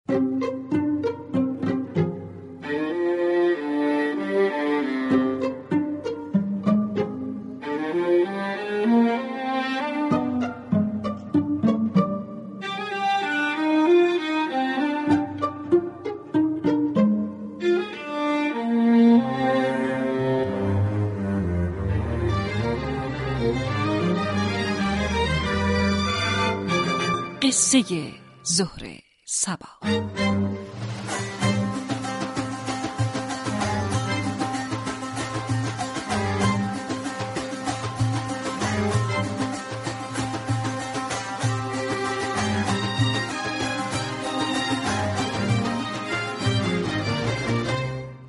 روز جمعه نهم مهر داستان كبوتر های تراس برای مخاطبان خوانده می شود .